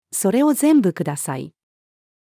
それを全部ください。-female.mp3